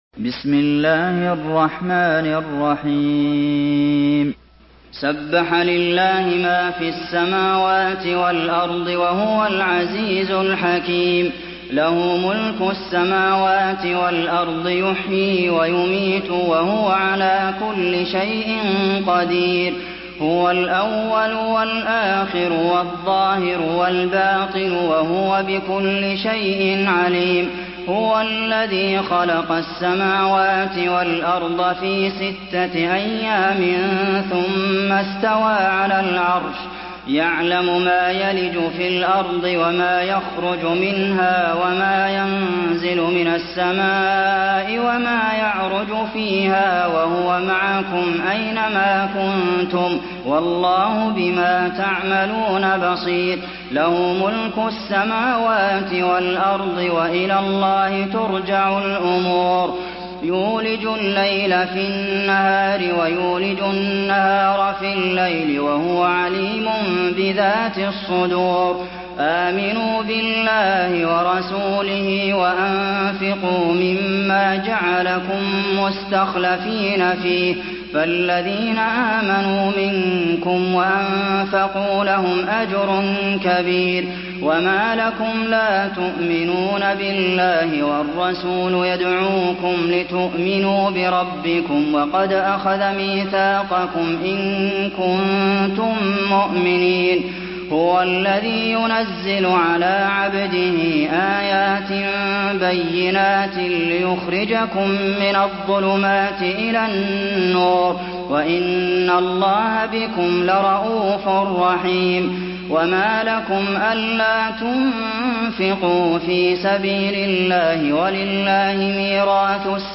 Surah Hadid MP3 in the Voice of Abdulmohsen Al Qasim in Hafs Narration
Murattal